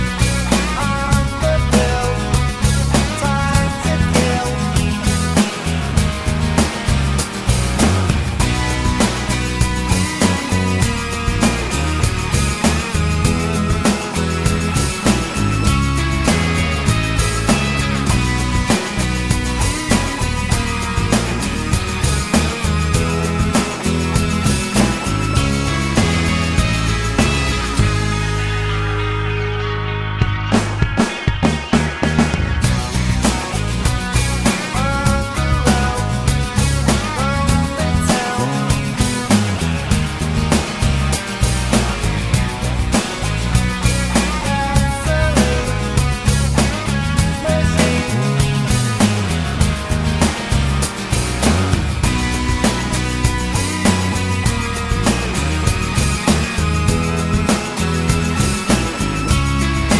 no Backing Vocals Indie / Alternative 3:49 Buy £1.50